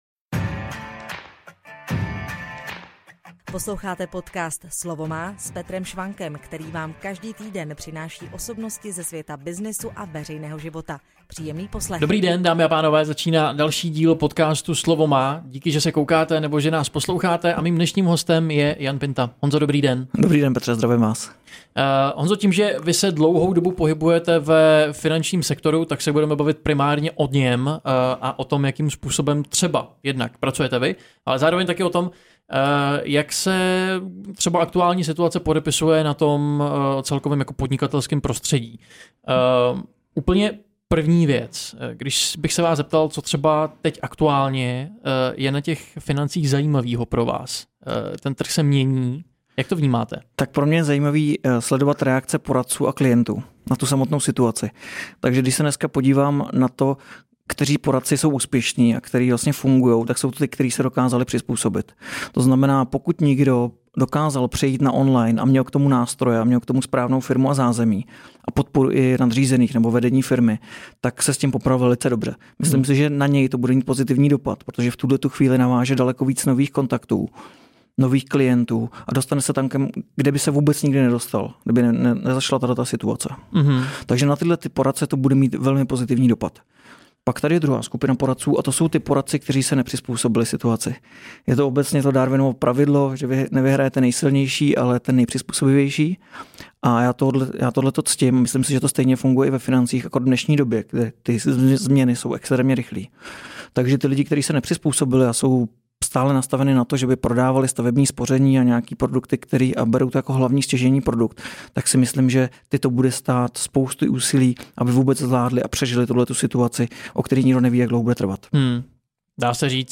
V rozhovoru se bavíme o finanční gramotnosti a o tom, jak lépe zacházet s vlastními penězmi v nejisté době.